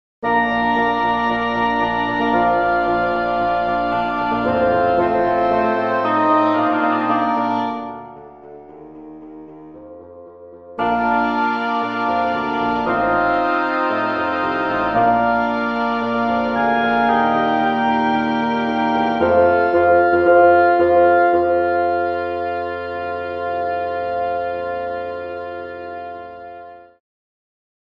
Woodwind Section
(Oboe, Cor Anglais / French Horn, Bassoon and Contrabassoon
DAL-Flute-Woodwinds-27-Woodwind-Section.mp3